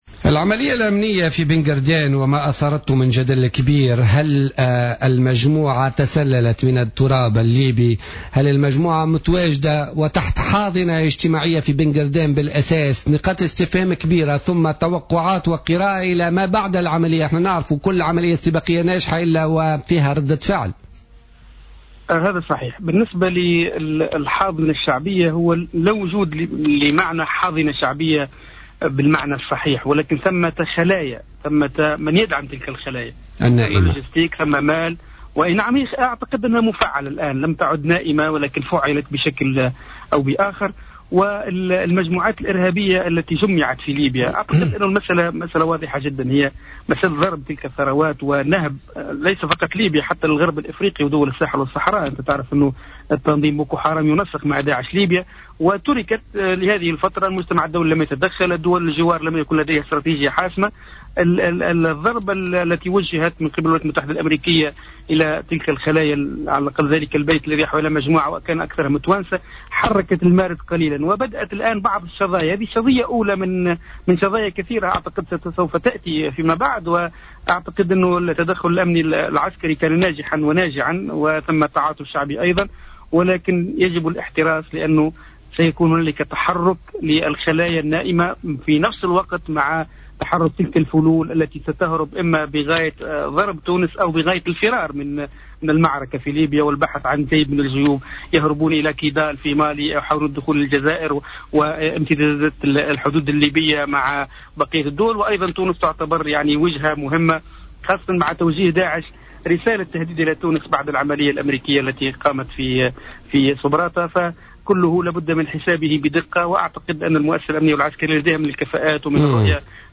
في حوار هاتفي مع الجوهرة اف ام خلال حصة بوليتيكا